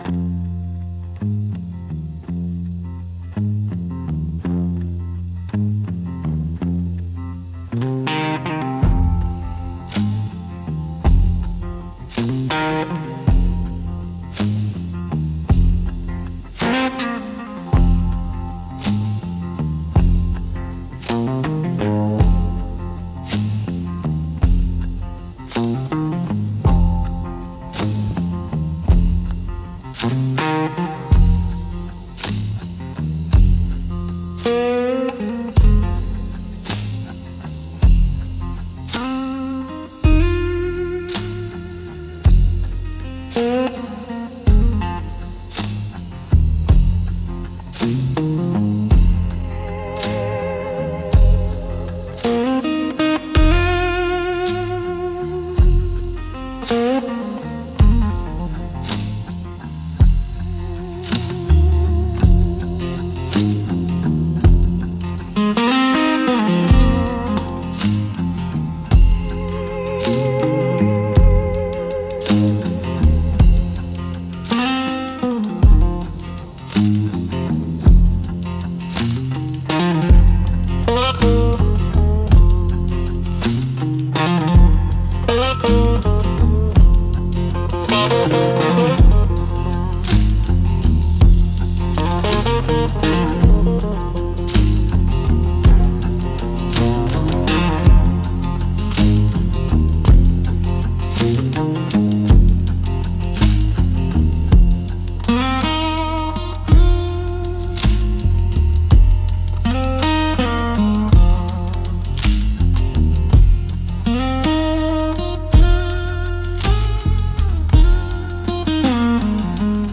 Instrumental - A Whiskey Prayer on the Porch